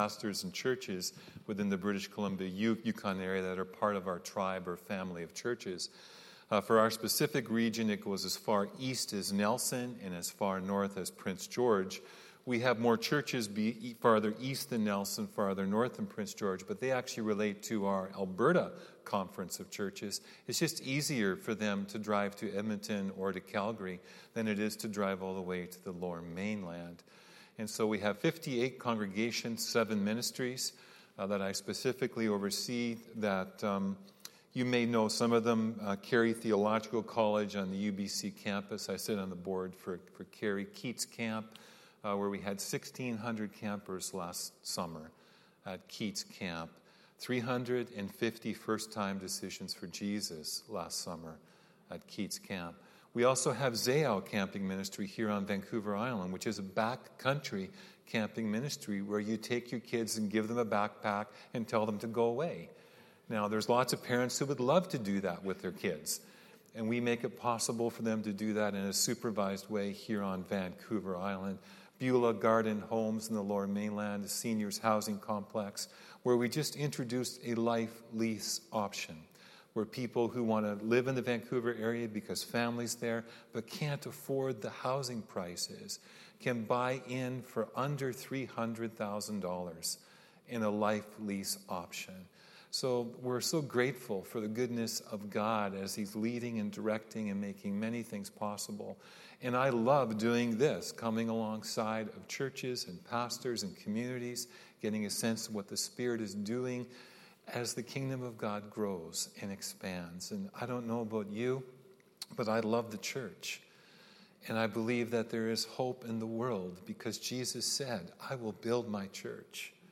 Sermons | Peninsula Mission Community Church
Guest Speaker